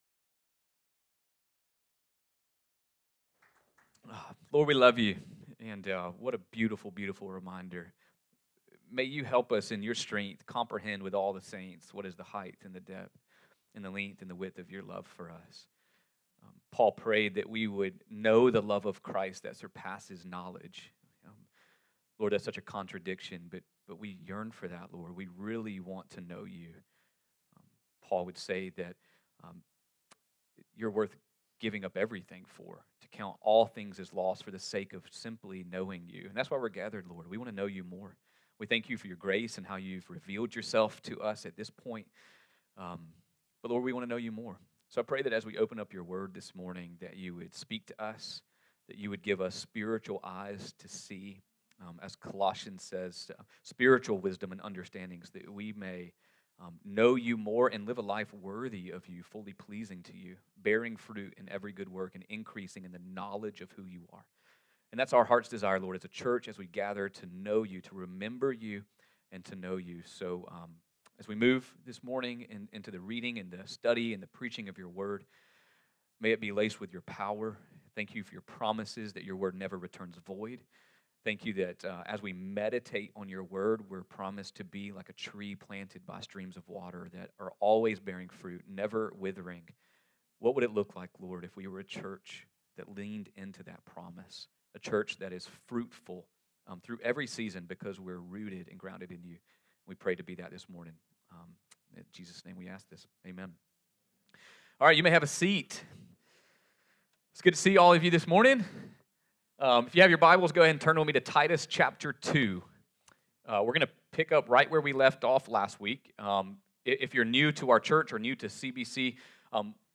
Sermons | Community Bible Church of Richmond Hill